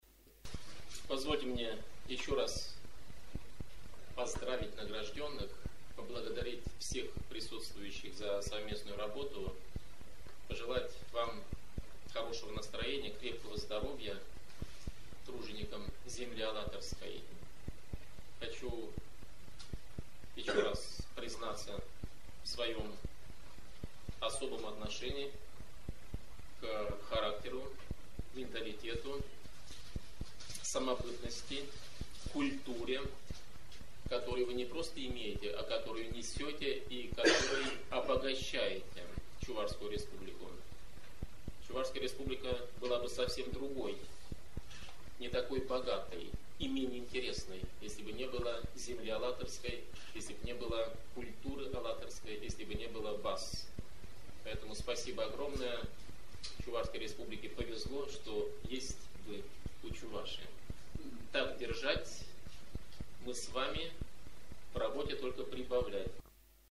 Об этом говорил в своем выступлении побывавший   24 августа в городе  Президент Чувашской Республики Н.В. Федоров.  Фрагмент записи выступления можно прослушать здесь.